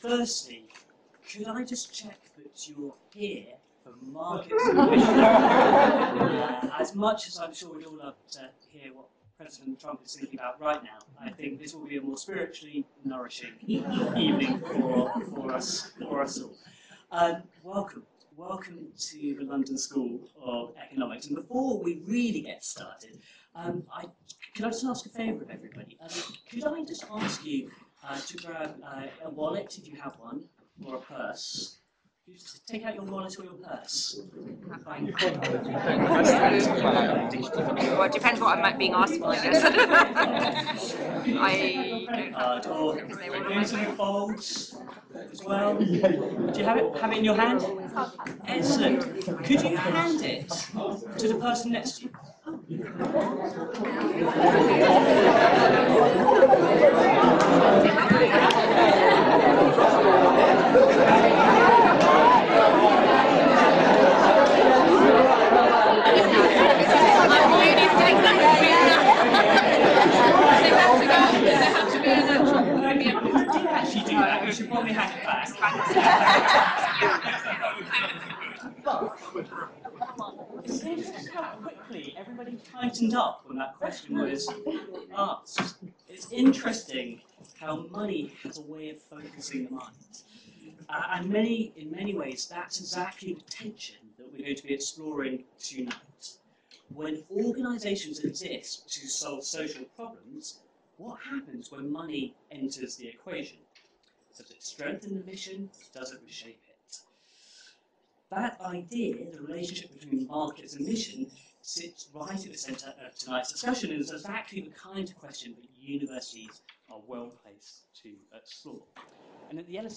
market-and-mission-public-lecture.mp3